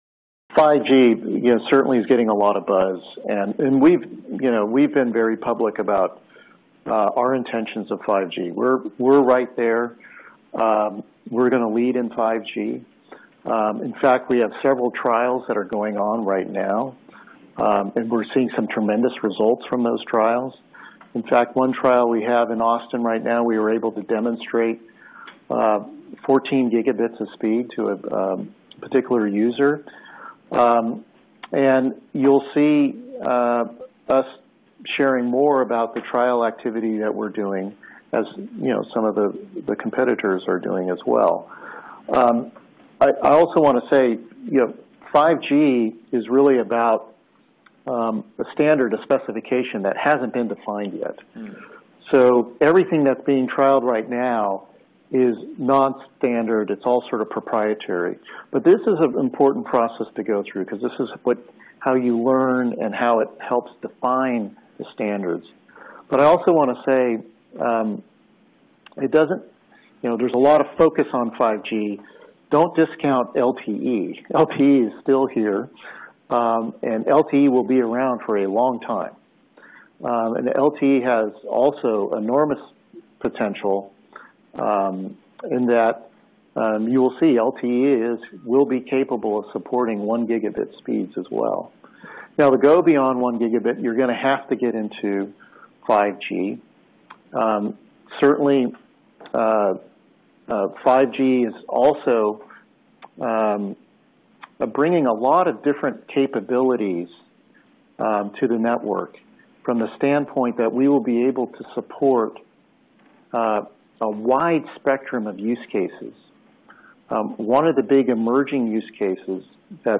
outlined the company's 5G activities and plans during a "fireside chat" at Nomura's 2016 Media, Telecom & Internet Conference